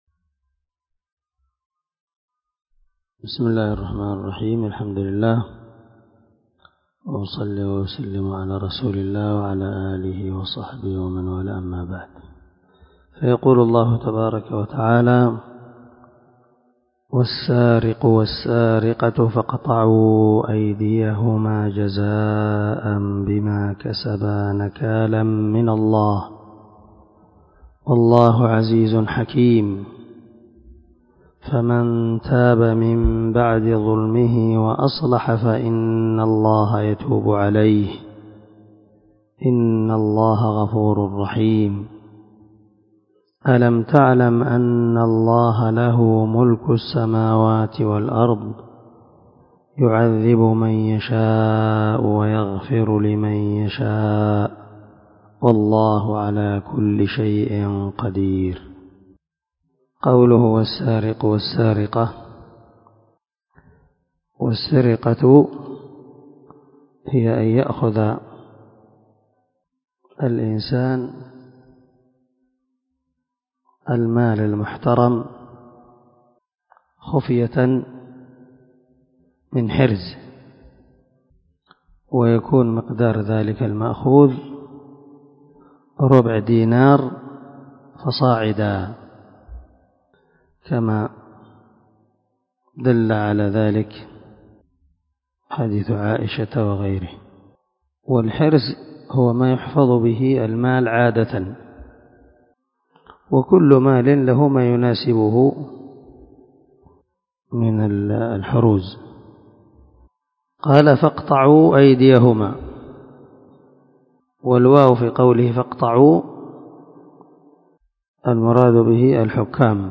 360الدرس 27 تفسير آية ( 38 – 40 ) من سورة المائدة من تفسير القران الكريم مع قراءة لتفسير السعدي
دار الحديث- المَحاوِلة- الصبيحة.